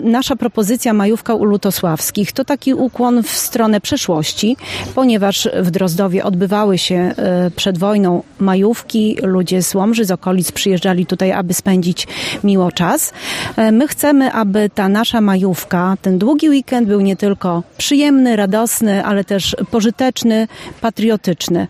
W niedzielne popołudnie (05.05.2024 r.) w Dworze Lutosławskich – Muzeum Przyrody w Drozdowie odbył się Piknik Patriotyczny z udziałem dzieci i ich rodzin w ramach Uroczystości Rocznicy Uchwalenia Konstytucji 3 Maja.